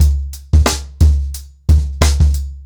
TrackBack-90BPM.35.wav